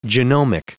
Prononciation du mot genomic en anglais (fichier audio)
Prononciation du mot : genomic